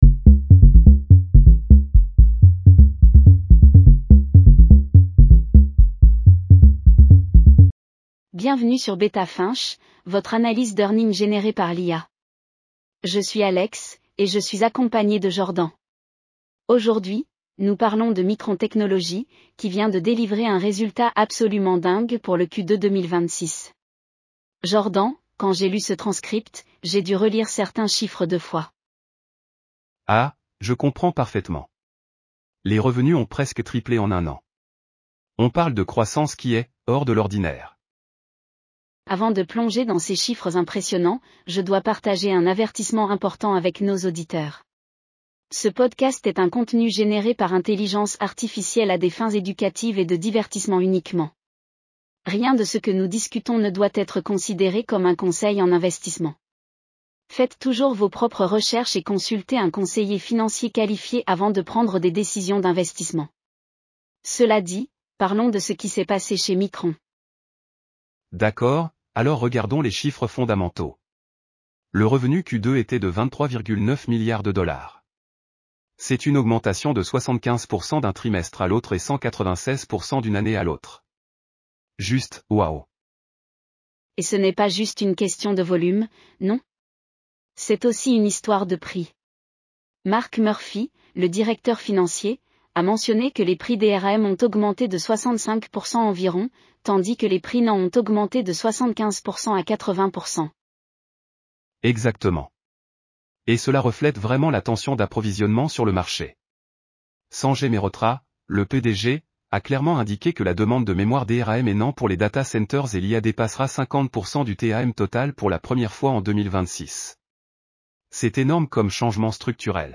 MU Q2 2026 Earnings Analysis